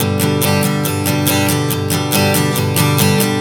Strum 140 G 02.wav